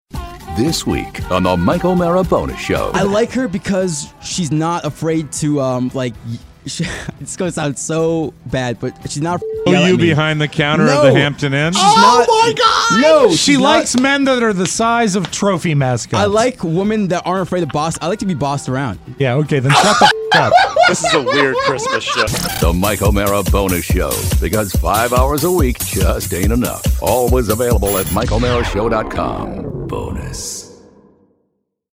It’s the Christmas party Bonus Show and things are getting crazy here at the studio.